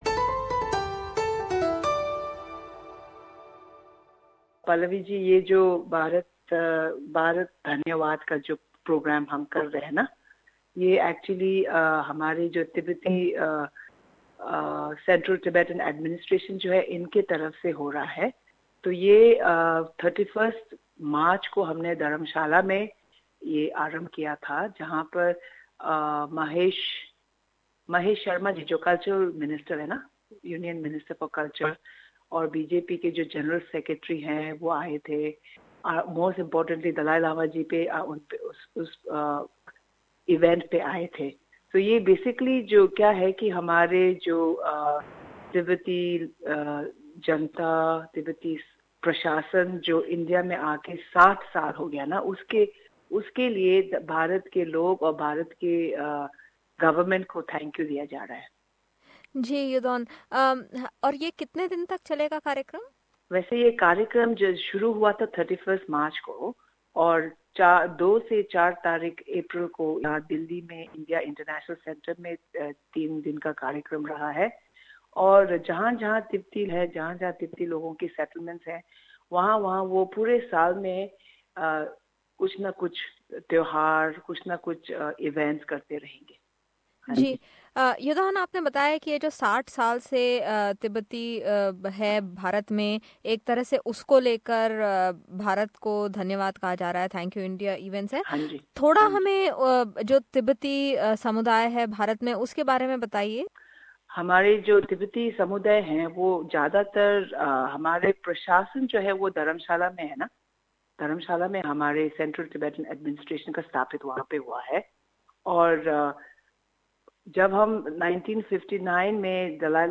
In an Australian exclusive, we spoke to Youdon Aukatsang who is an elected member of the Tibetan Parliament in Exile.